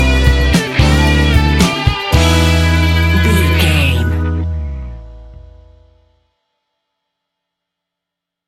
Ionian/Major
A♭
house
synths
techno
trance
instrumentals